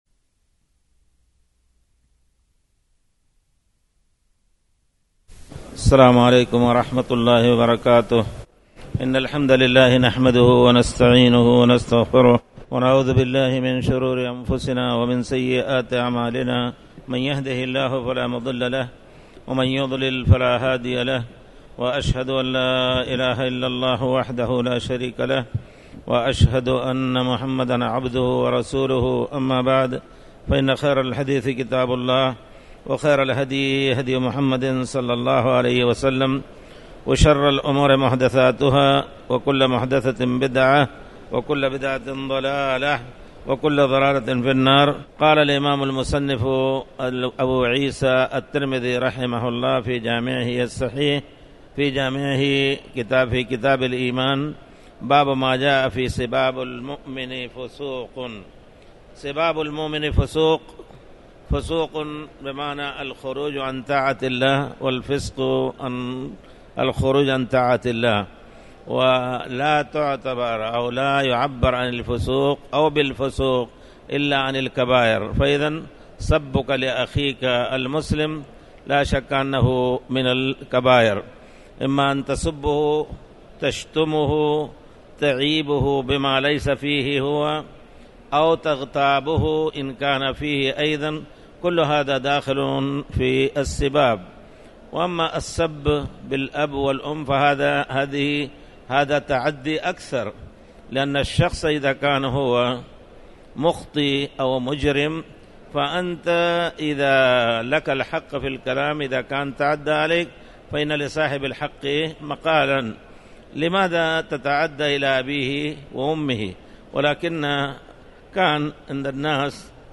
تاريخ النشر ١٩ رجب ١٤٣٩ هـ المكان: المسجد الحرام الشيخ